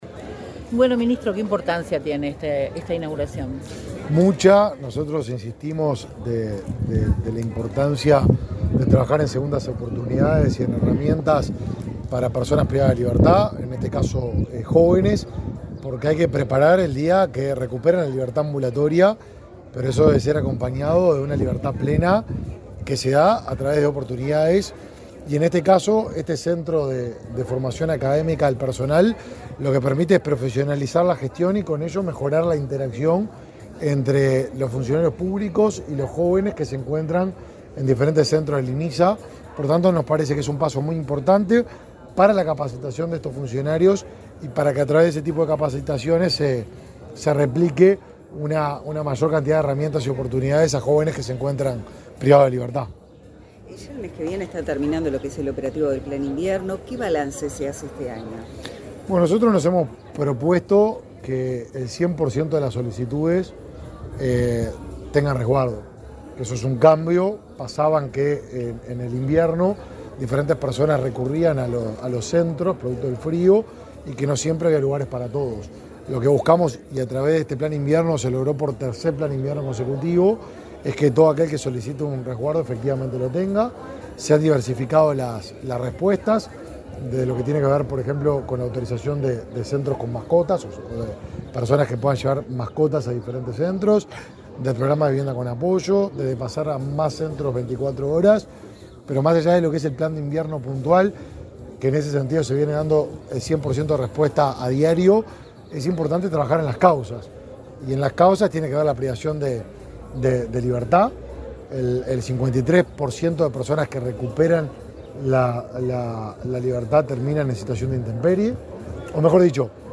Declaraciones del ministro de Desarrollo Social, Martín Lema
Declaraciones del ministro de Desarrollo Social, Martín Lema 21/09/2023 Compartir Facebook X Copiar enlace WhatsApp LinkedIn El ministro de Desarrollo Social, Martín Lema, participó, en Montevideo, en la inauguración del Centro Académico de Formación del Instituto Nacional de Inclusión Social Adolescente (Inisa). Luego dialogó con la prensa.